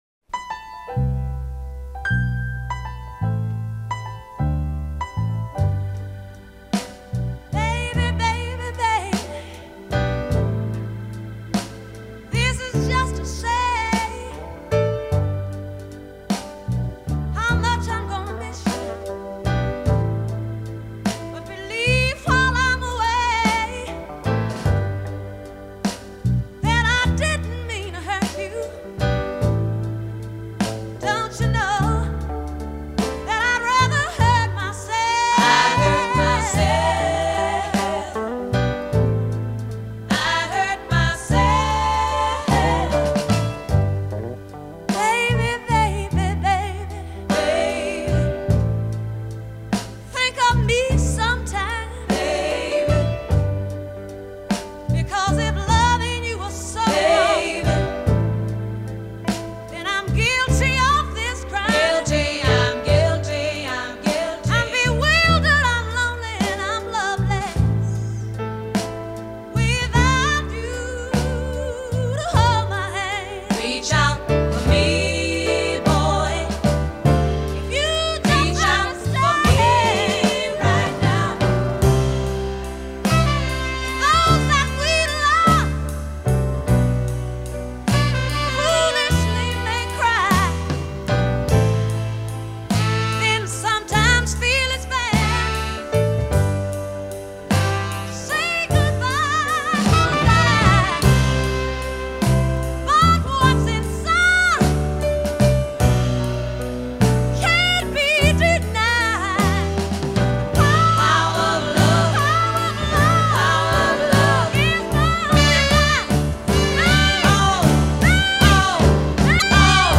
a slow-burner that features
on the piano herself.